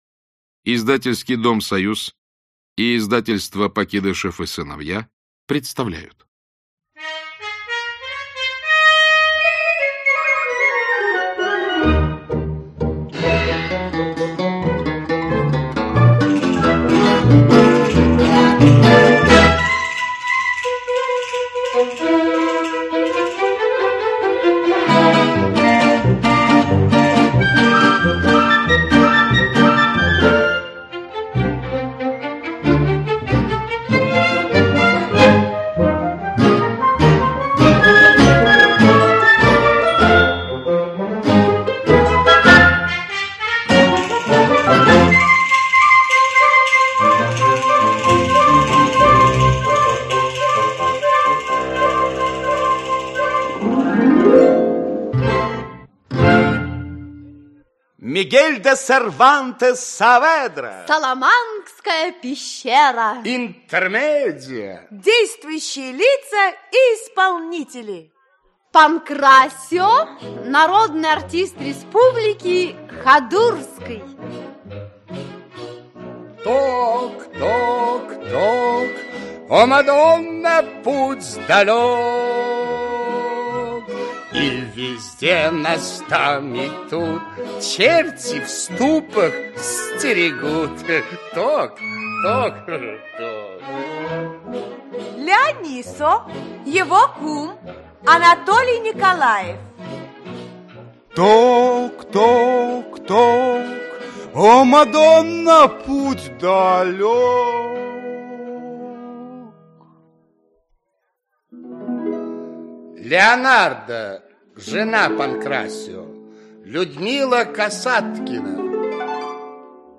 Аудиокнига Саламанская пещера (аудиоспектакль) | Библиотека аудиокниг
Aудиокнига Саламанская пещера (аудиоспектакль) Автор Мигель де Сервантес Сааведра Читает аудиокнигу Георгий Менглет.